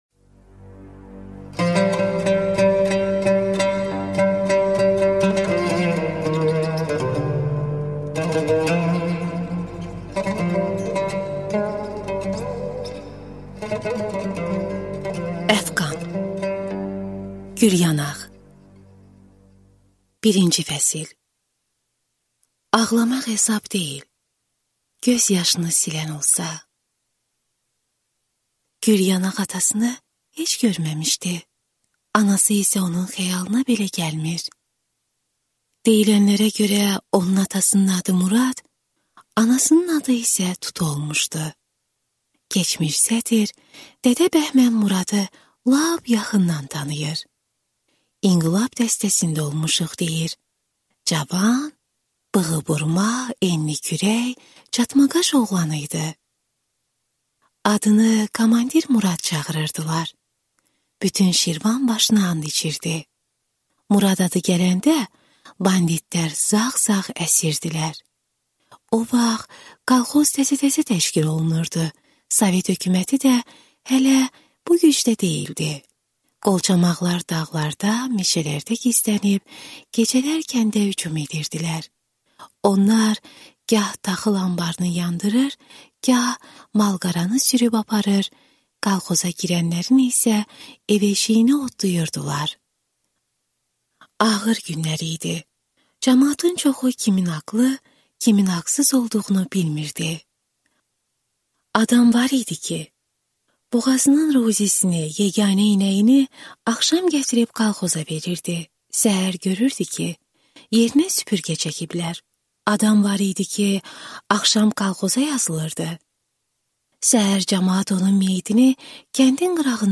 Аудиокнига Gülyanaq | Библиотека аудиокниг
Прослушать и бесплатно скачать фрагмент аудиокниги